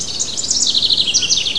housewren.wav